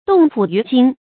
注音：ㄉㄨㄙˋ ㄆㄨˇ ㄧㄩˊ ㄐㄧㄥ
讀音讀法：
凍浦魚驚的讀法